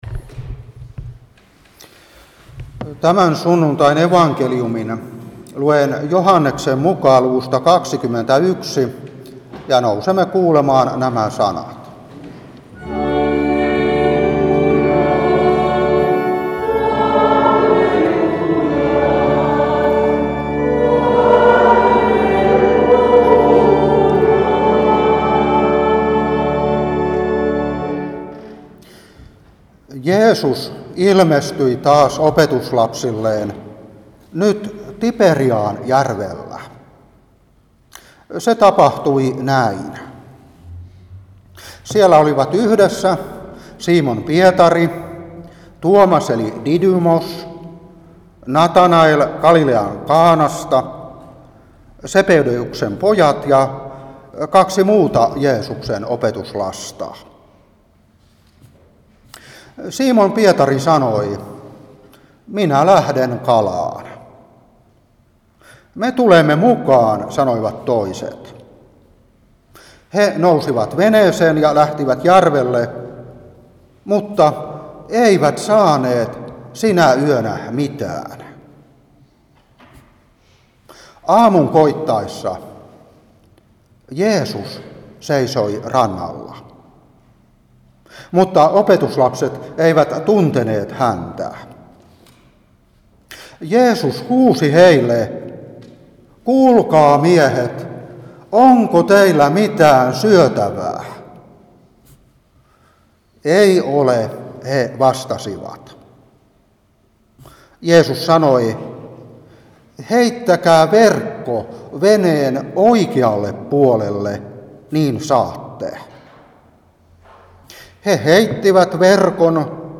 Saarna 2023-4.